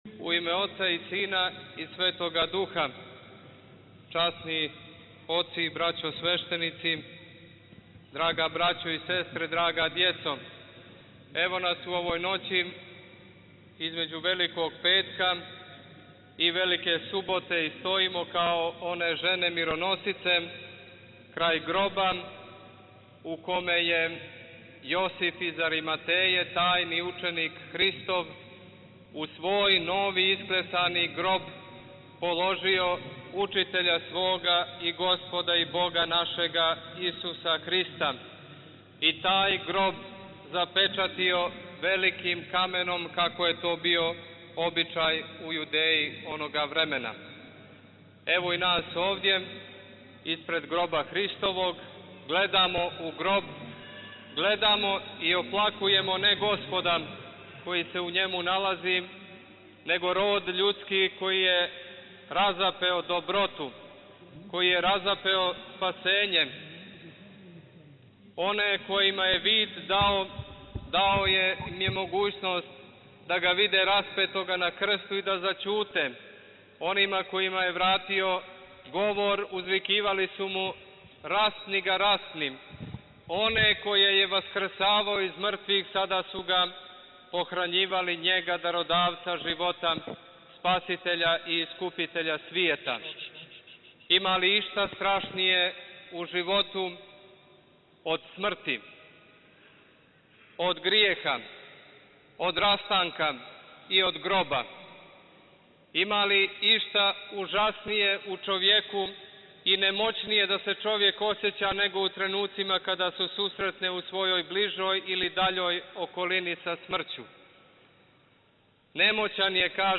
Велики петак у Саборном храму у Подгорици